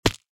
fallbig.ogg